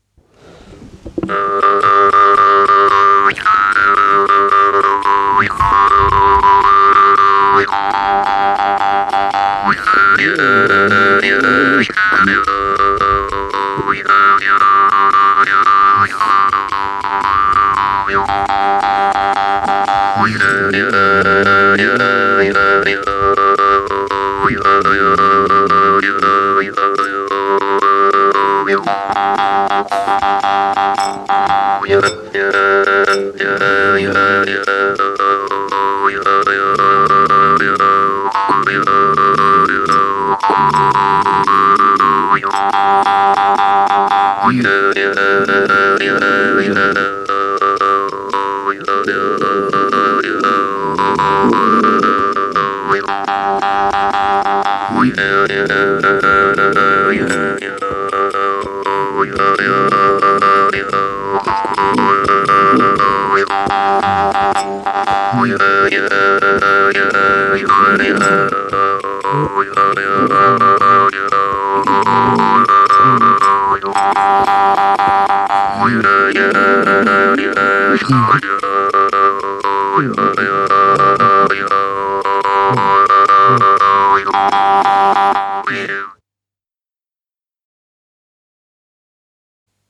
Música mapuche (Comunidad Laguna, Lumaco)
Música vocal
Música tradicional